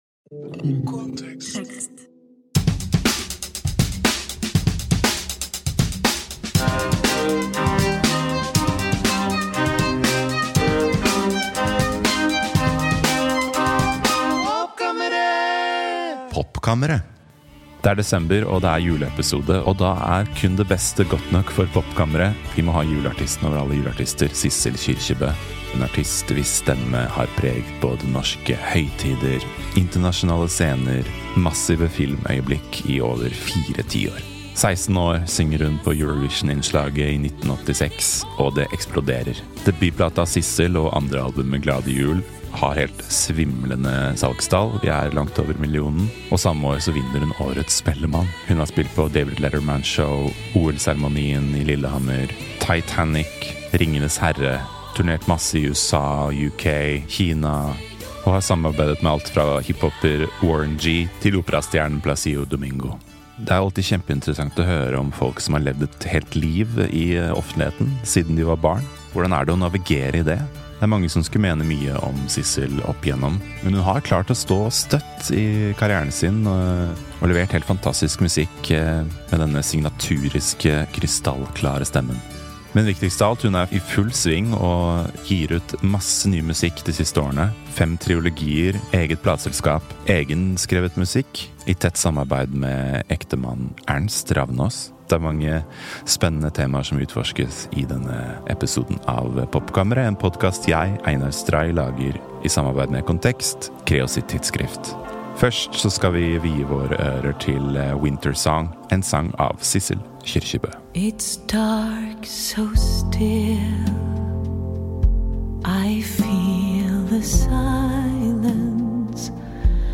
Music Interviews